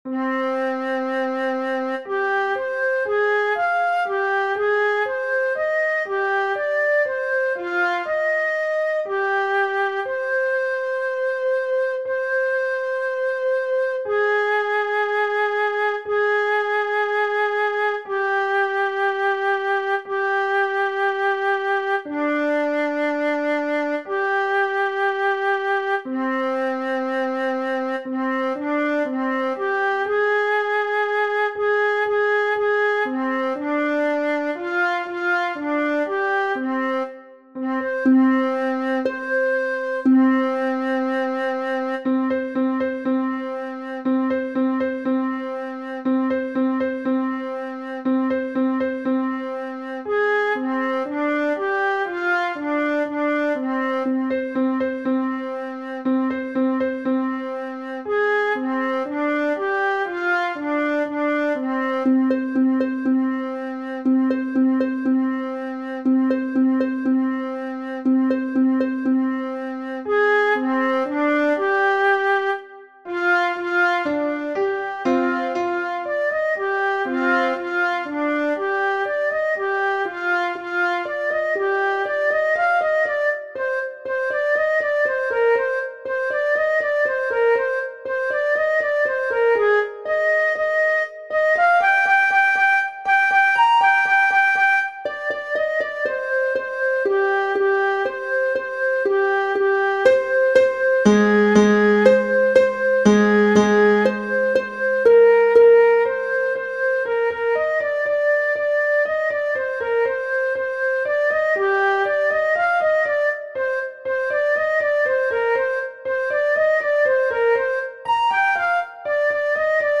Flute and Piano Cinematography